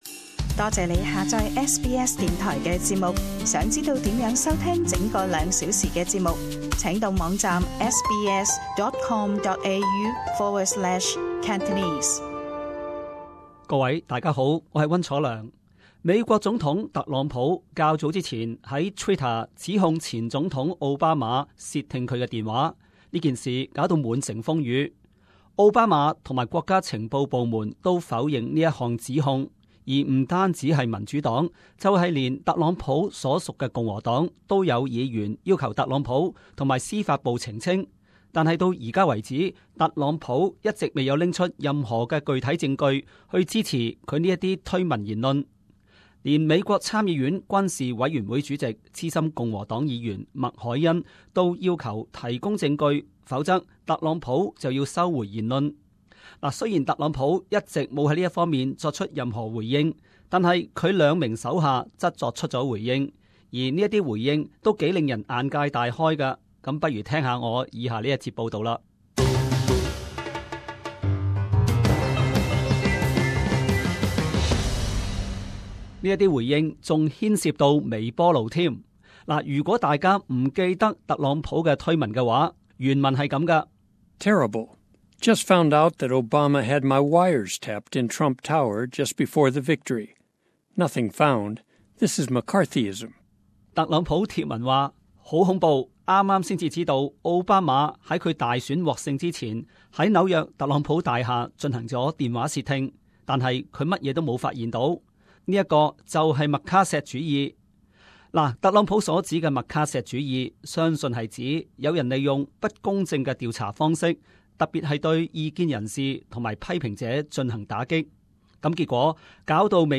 【时事报导】 白宫要员为窃听风波解画